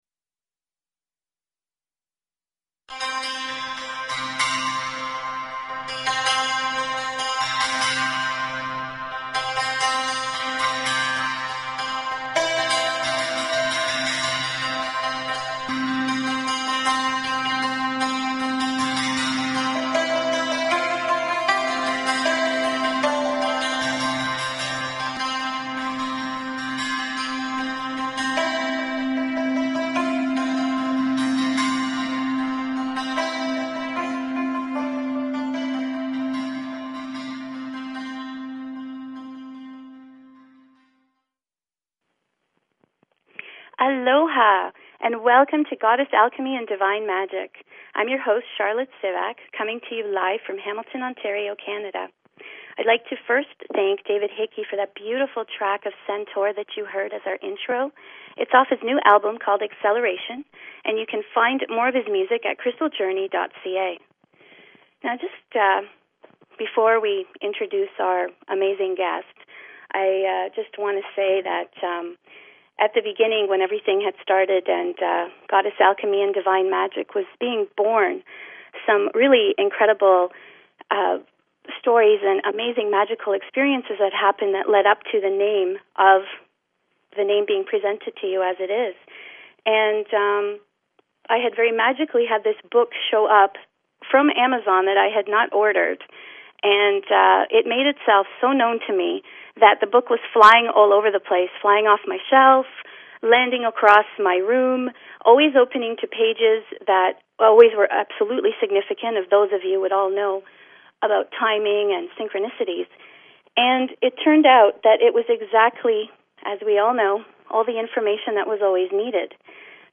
Each broadcast is infuses with ascension Light body activations, transmissions, shadow wisdom transformations and meditative journeys assisting with awakening, anchoring, and actualization of